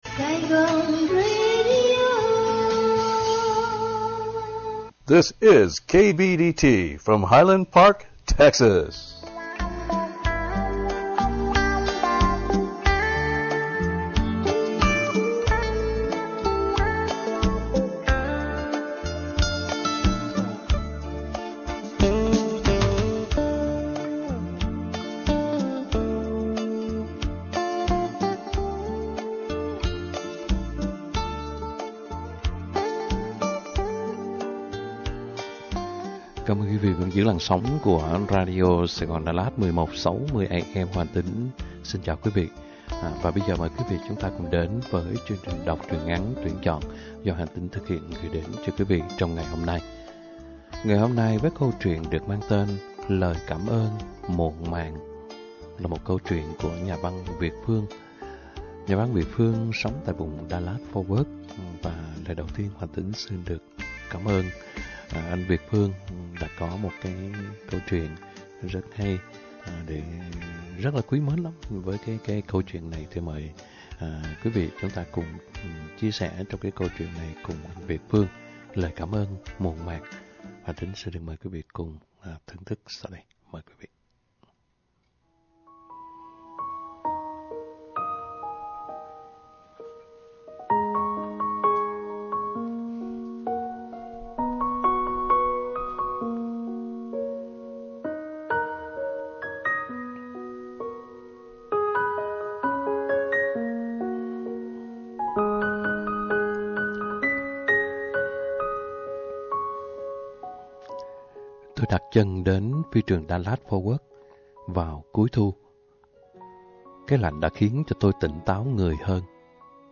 Đọc truyện - Những thằng già nhớ mẹ - 12/03/2022